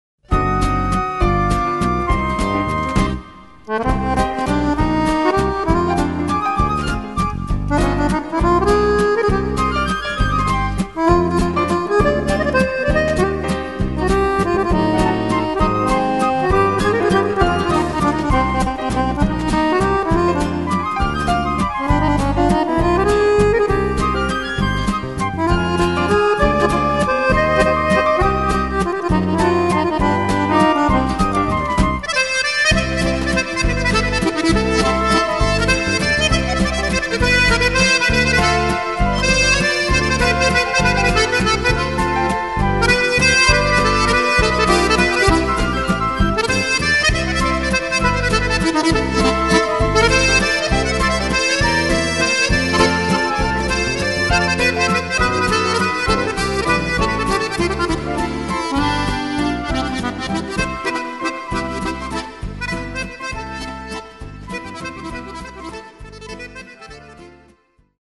Nous animons des soirées prestigieuses,des bals populaires, des thés dansants,des diners dansants,messe de mariage,mariages,soirées privées,soirées d'anniversaire ou départs en retraite,animations en maison de retraite etc..,
Chanteur,guitare,trompette               Photo
Accordéon                          Photo
Batteur,Chant,Animateur             Photo
Valse,boston,mazurka,polka,madison,samba,tcha-tcha,baion,rock,disco,paso,marche,viennoise,rumba,tango,bolèro,bossa nova,mambo,etc...